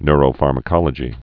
(nrō-färmə-kŏlə-jē, nyr-)